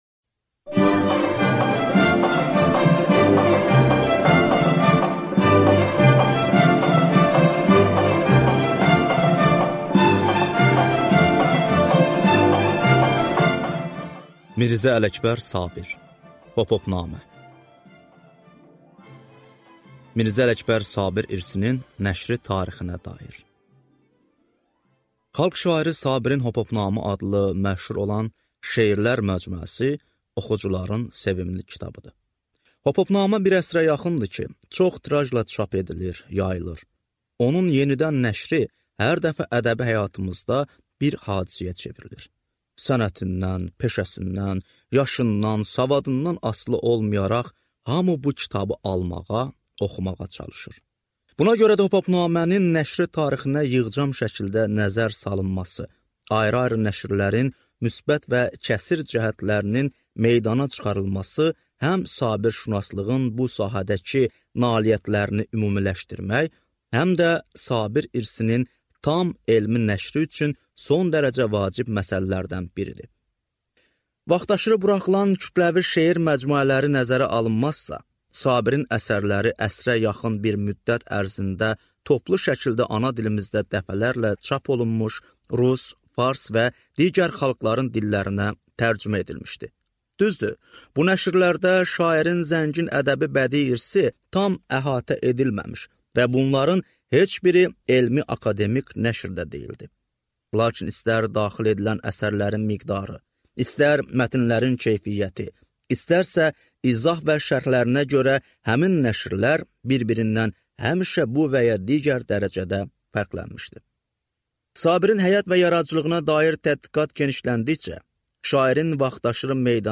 Аудиокнига Hophopnamə 1-ci cild | Библиотека аудиокниг